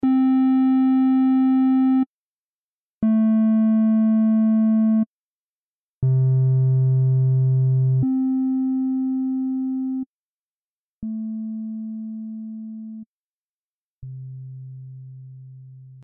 Datei) 632 KB OSC Sound mit kontinuierlichem Filterverlauf (Frequenz 18,5 kHz bis 30,5 Hz, Resonanz 0,30) 1
OSC_Sound_kontinulierlicher_Filterverlauf.mp3